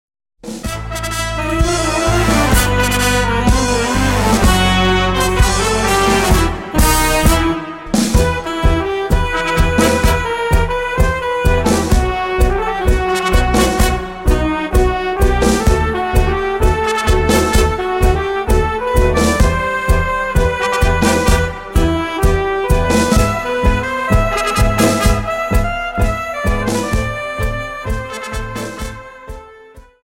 Dance: Tango